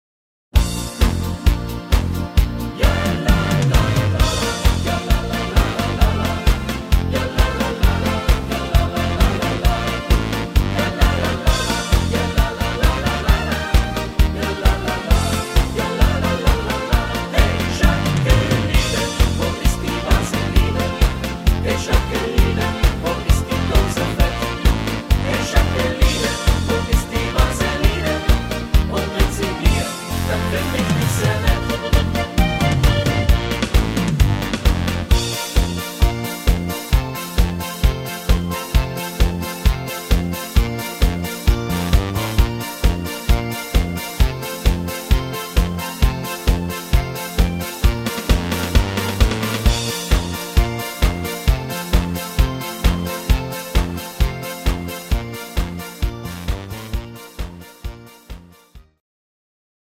Rhythmus  Party Marsch
Art  Deutsch, Fasching und Stimmung, Party Hits